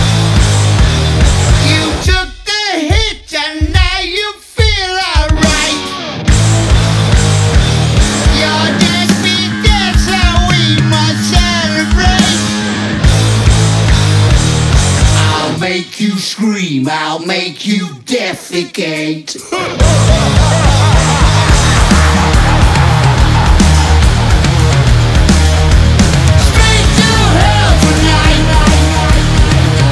Genre: Metal